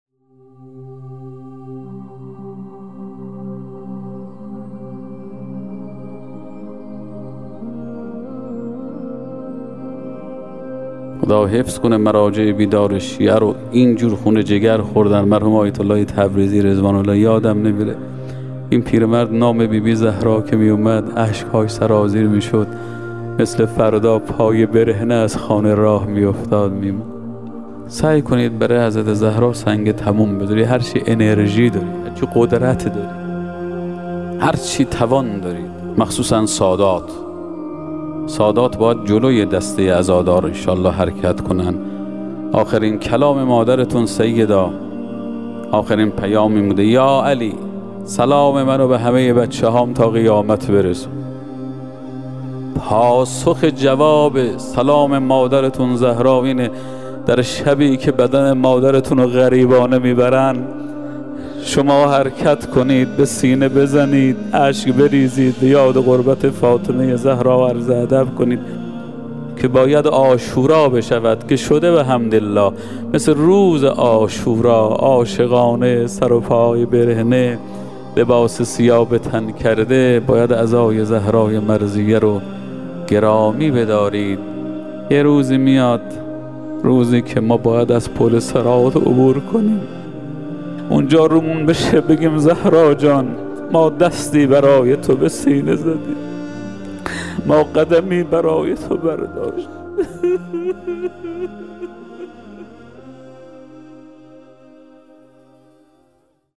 شهادت حضرت زهرا (س) آموزه‌ای متعالی در باب دفاع از آرمان‌های دینی و ارزش‌های اسلام است. ایکنا به مناسبت ایام سوگواری شهادت دخت گرامی آخرین پیام‌آور نور و رحمت، مجموعه‌ای از سخنرانی اساتید اخلاق کشور درباره شهادت ام ابی‌ها (س) با عنوان «ذکر خیر ماه» منتشر می‌کند.